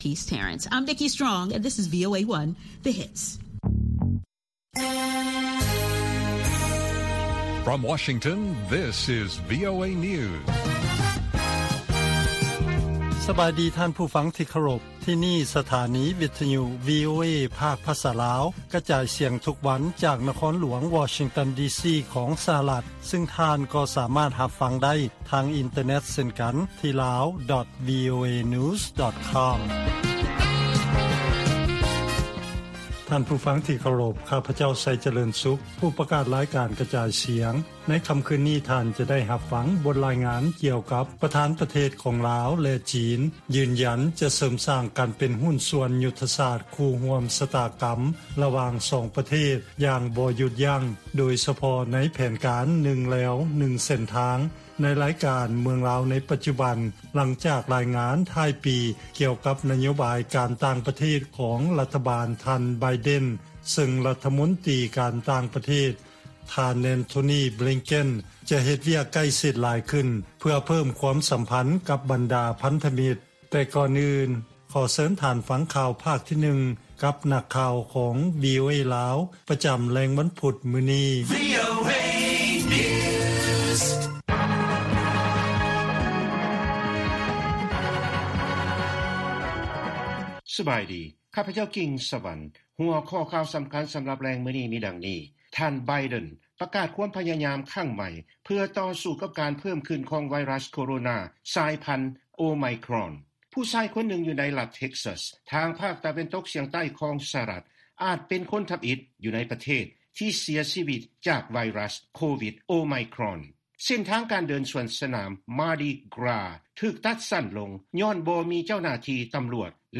ລາຍການກະຈາຍສຽງຂອງວີໂອເອ ລາວ: ທ່ານ ໄບເດັນ ປະກາດຄວາມພະຍາຍາມຄັ້ງໃໝ່ ເພື່ອຕໍ່ສູ້ກັບການເພີ່ມຂຶ້ນຂອງໄວຣັສໂຄໂຣນາ ສາຍພັນໂອໄມຄຣອນ